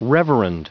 Prononciation du mot : reverend
reverend.wav